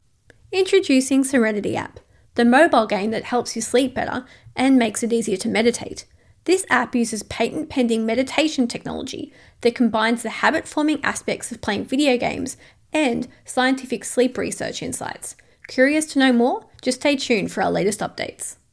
I sound believable, natural and what was once described as "listenable" - my main focus is on eLearning narration. Hard sell, soft sell, natural, easy to listen to....
0311explainervideo.mp3